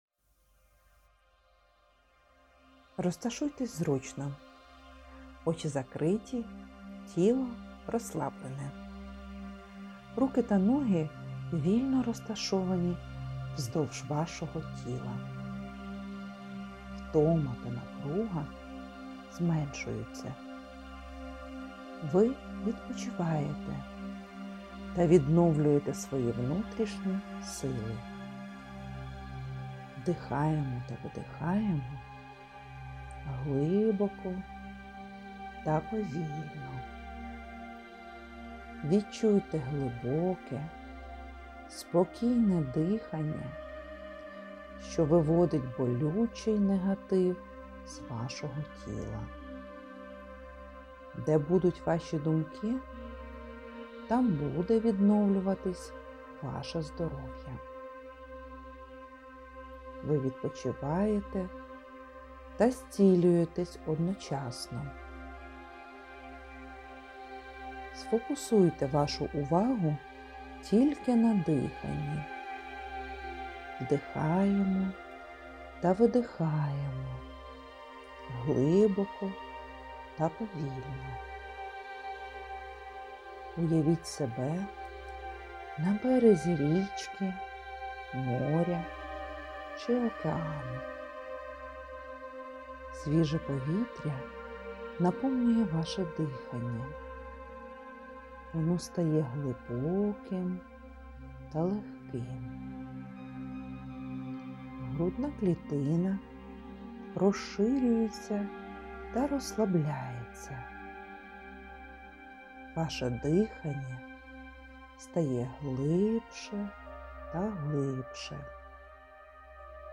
autotrening_dyhannya_svitlom.mp3